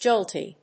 /dʒóʊlti(米国英語), dʒˈəʊlti(英国英語)/